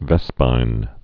(vĕspīn)